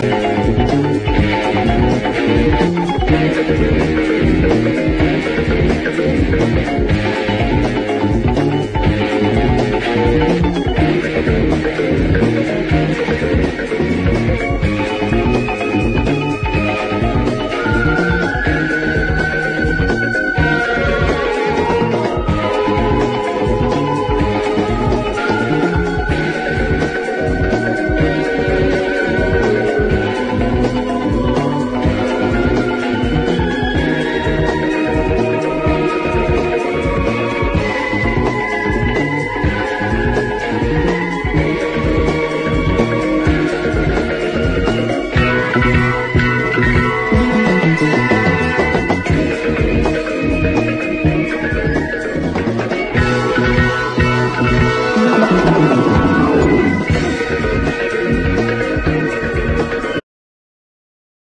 PROGRESSIVE ROCK
アブストラクト・ファンキー・グルーヴ
前作よりもよりアヴァンギャルドで変態なイタリアン・プログレ2ND！